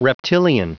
Prononciation du mot reptilian en anglais (fichier audio)
Prononciation du mot : reptilian